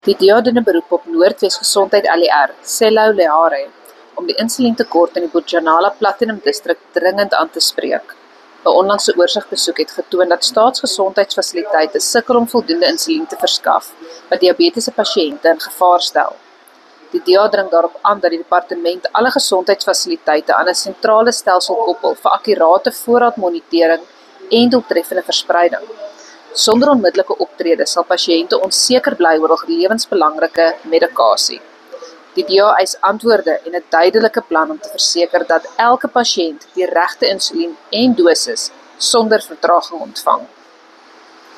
Note to Broadcasters: Please find attached soundbites in